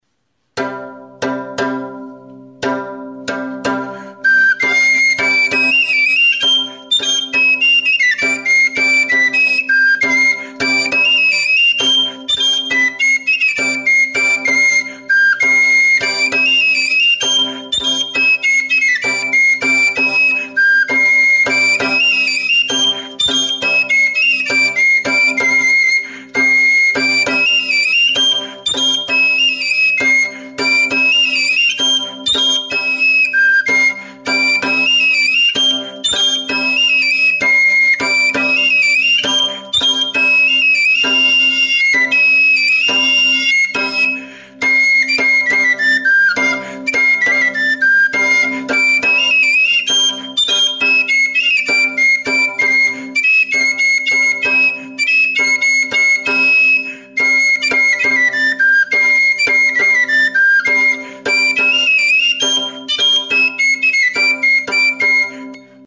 Recorded with this music instrument.
DANBURIA; TTUNTTUNA; SOINUA; SALTERIOA
Stringed -> Beaten
Sei sokazko zuberotar danburia da.